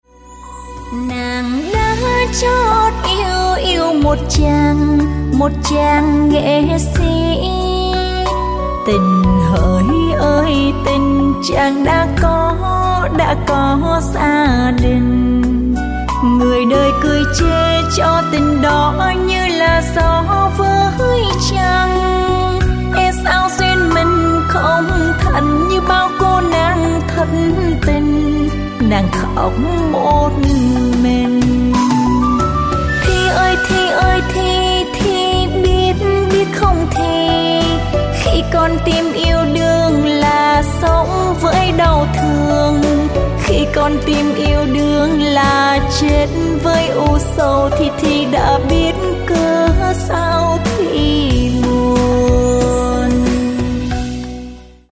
Bolero/ Trữ tình